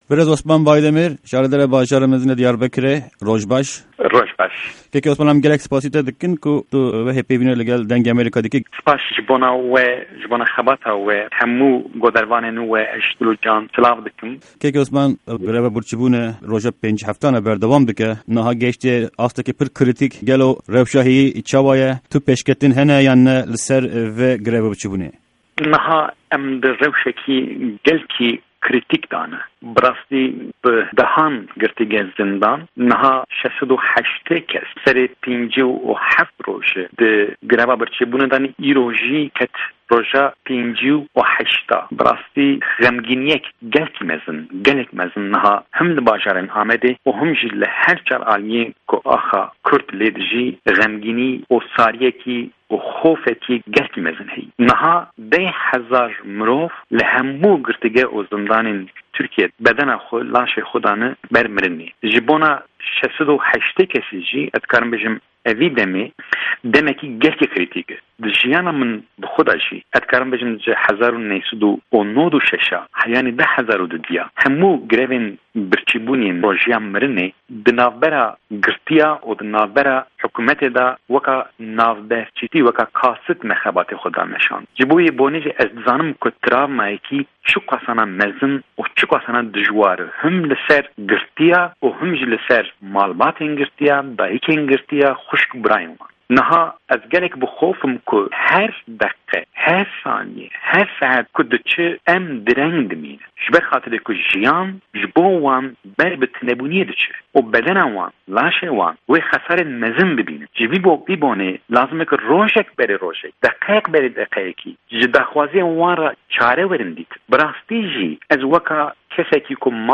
Hevpeyvîn_ligel_Osman_Baydemir